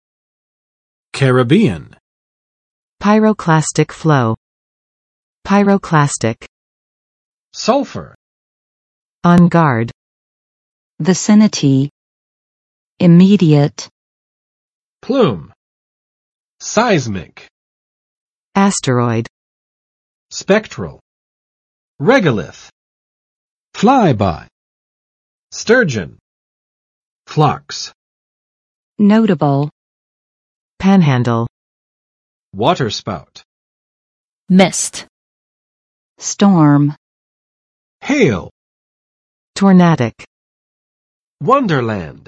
[͵kærəˋbiən] adj. 加勒比人的；加勒比海的；加勒比语的